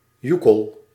Ääntäminen
France: IPA: [kle.baʁ] Paris